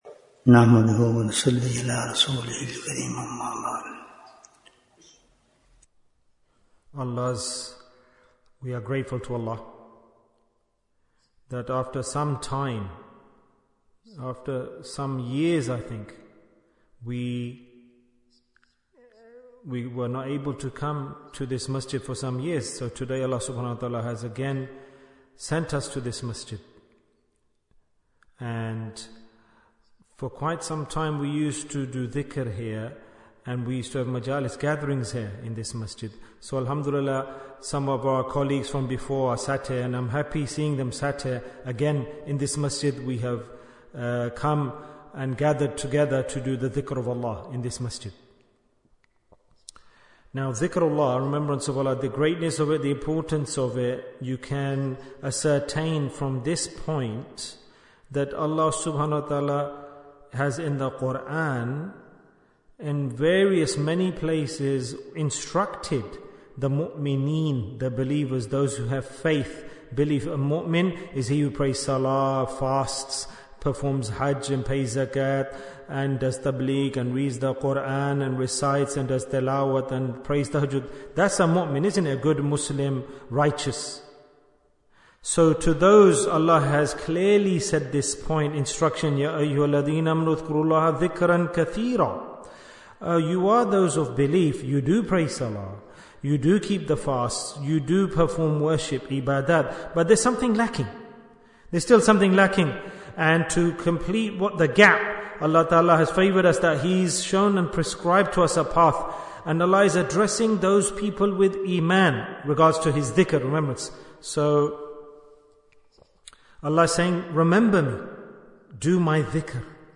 Listen to this Order of Allah Ta'ala to Succeed Bayan, 25 minutes13th November, 2024